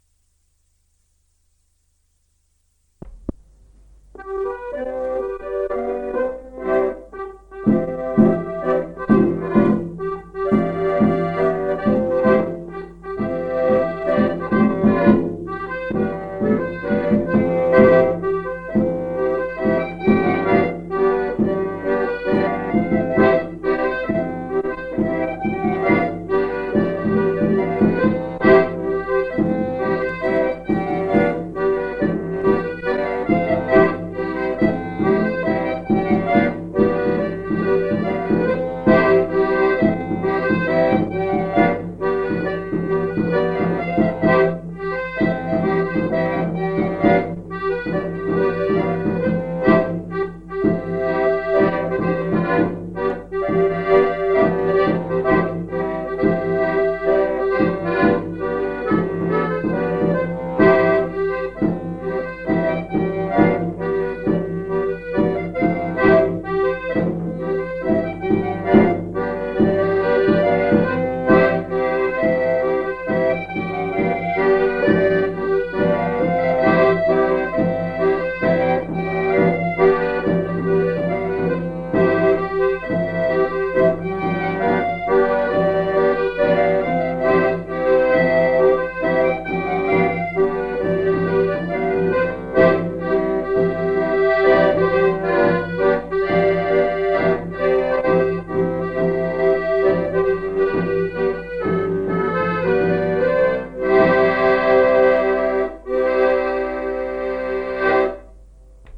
Découvrez notre collection d'enregistrements de musique traditionnelle de Wallonie
Type : chanson de saut à la corde Aire culturelle d'origine